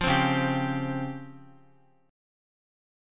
DbM#11 chord